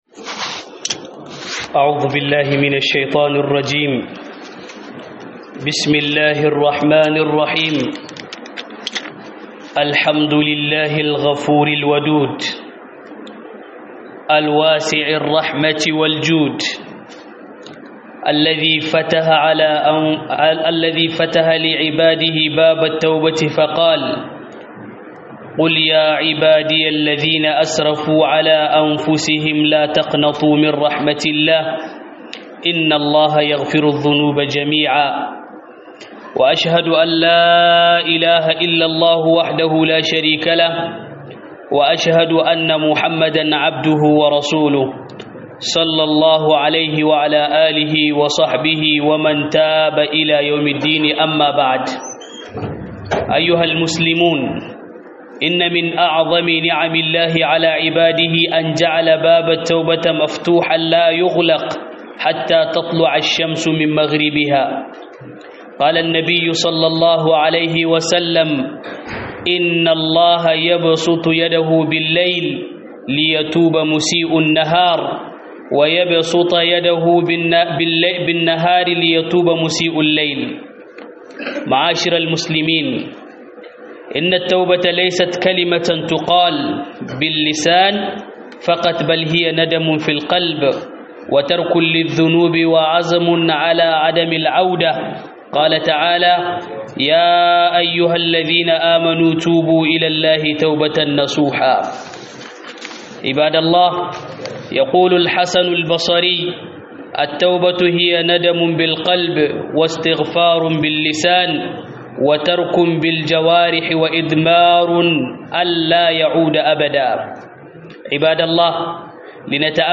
Huɗubar juma'a Tuba zuwa ga Allah - HUDUBA